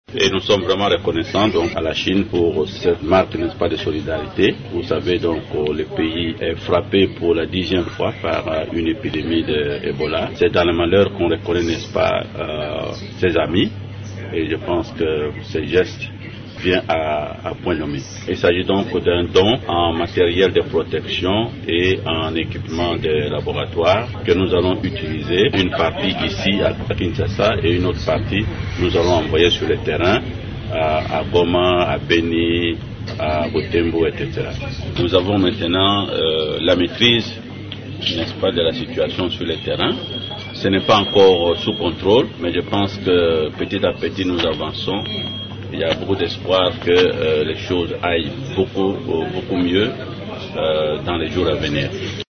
Le Dr Jean-Jacques Muyembe, coordonnateur de la riposte contre Ebola, affirme que l’apport de la Chine est important pour les équipes de riposte sur le terrain.
Vous pouvez l'écouter dans cet extrait sonore.